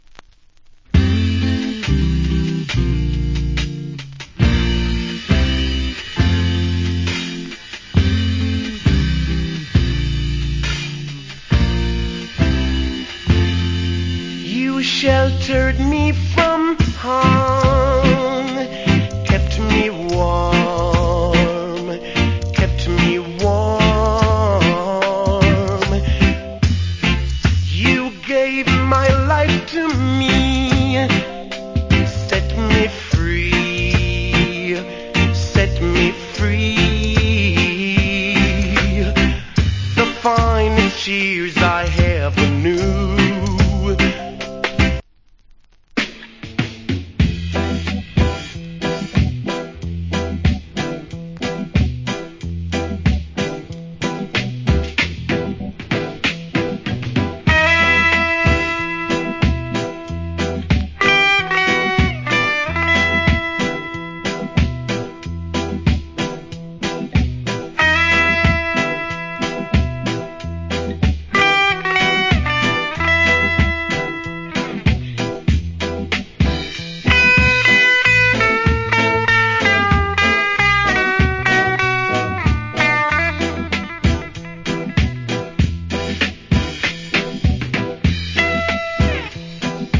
category Reggae
Great Reggae Vocal.
/ Killer Reggae Inst.